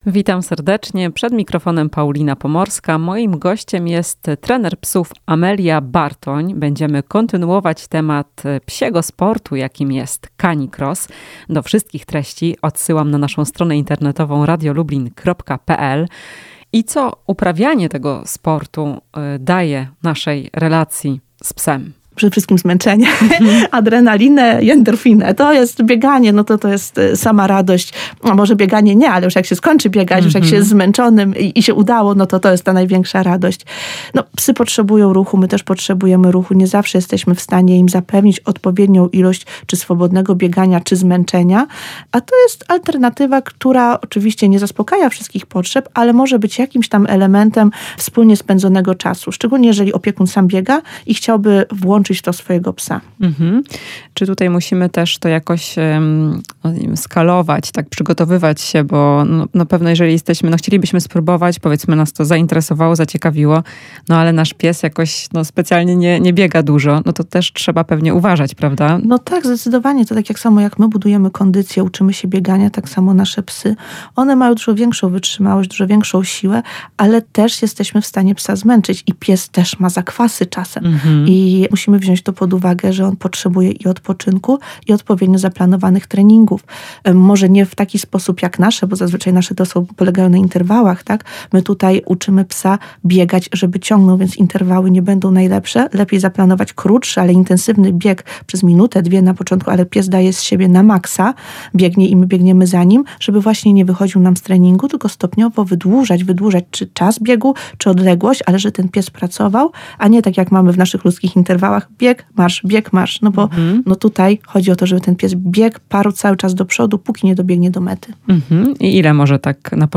W "Chwili dla pupila" powiemy jakie korzyści dla naszej relacji z psem może przynieść CaniCross. Rozmowa z trenerem psów